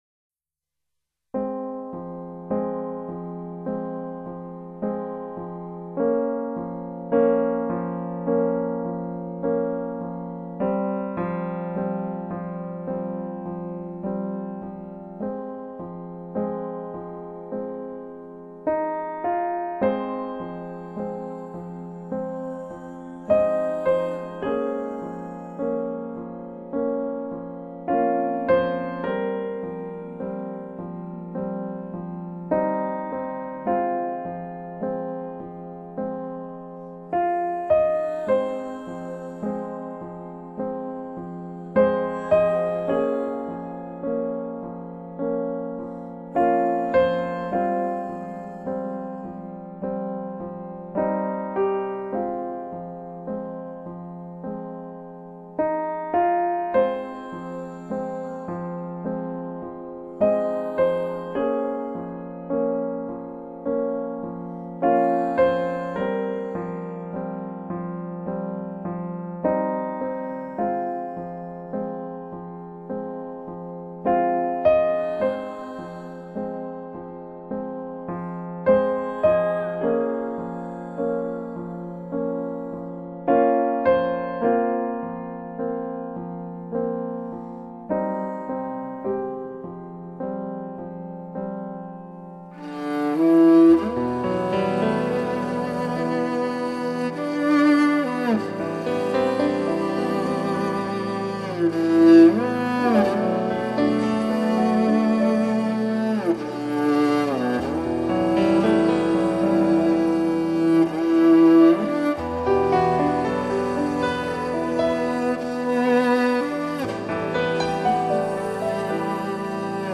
实地采风，实地收音，去尽一切斧凿雕饰。